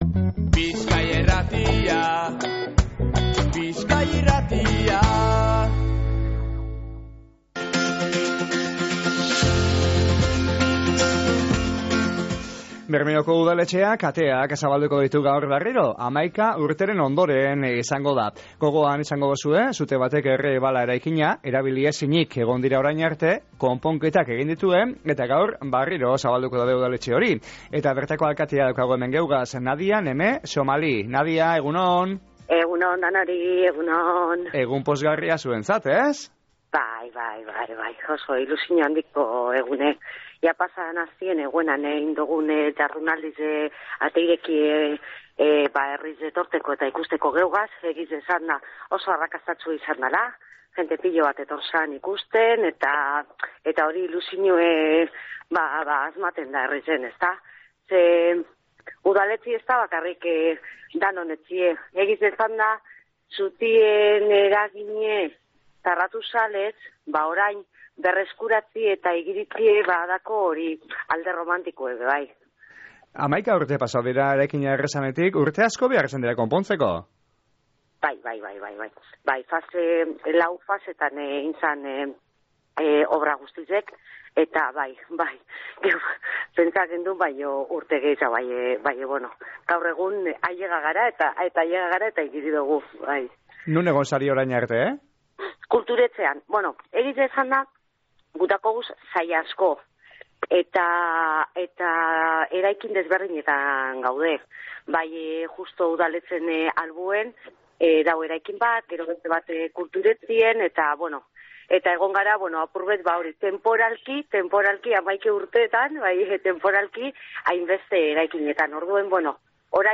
"Herritarrek eskatutako batzarrak udaletxe historikoan egingo dodaz" esan deusku Bermeoko alkateak